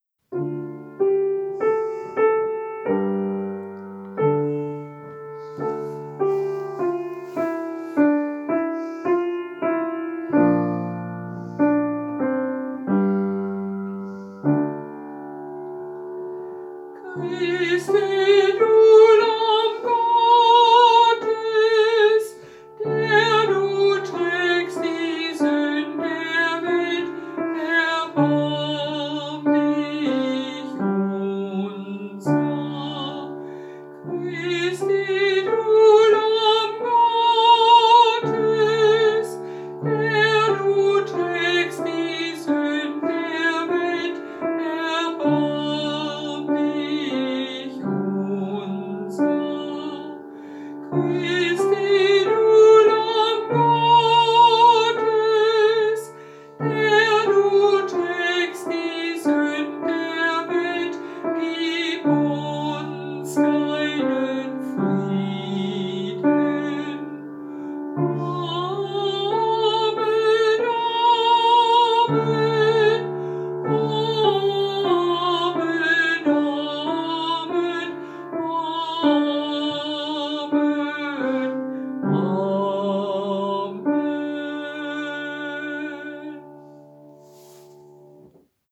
Christe du Lamm Gottes Gesang und Klavier